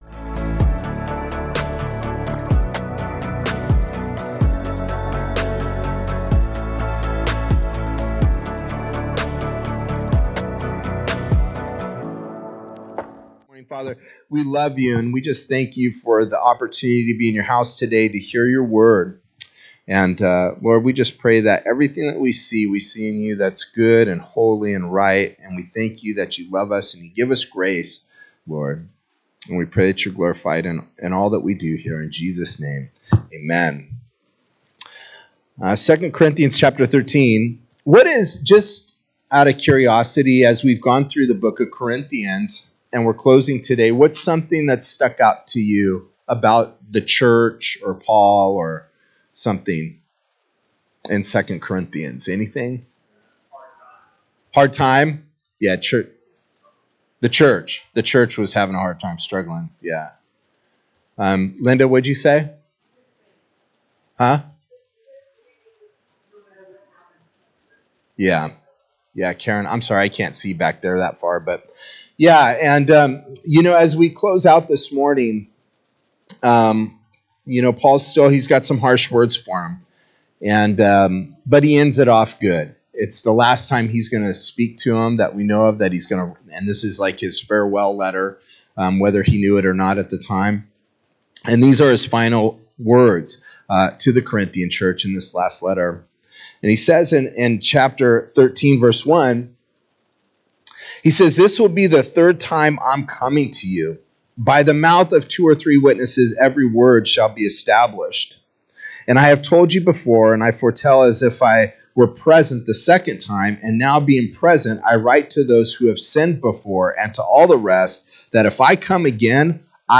SERMONS - Ark Bible Church